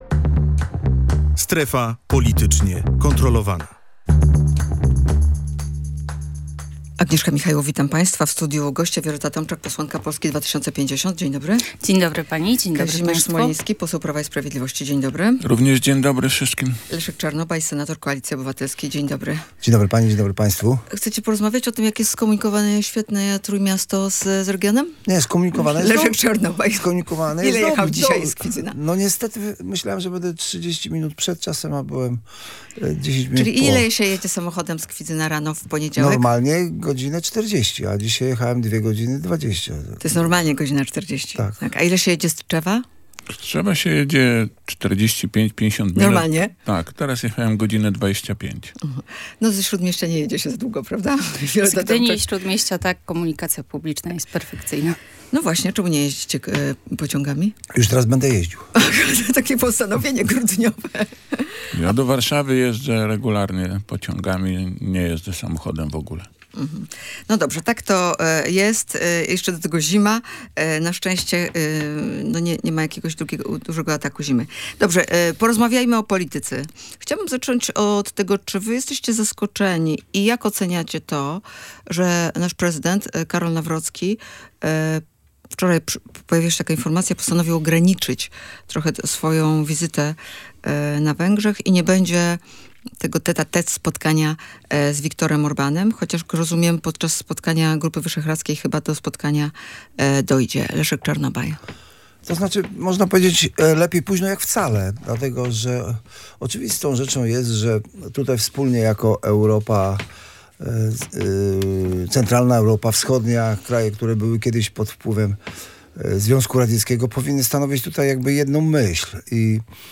Decyzję głowy państwa ocenili: Leszek Czarnobaj, senator Koalicji Obywatelskiej, Kazimierz Smoliński, poseł Prawa i Sprawiedliwości, oraz Wioleta Tomczak, posłanka Polski 2050.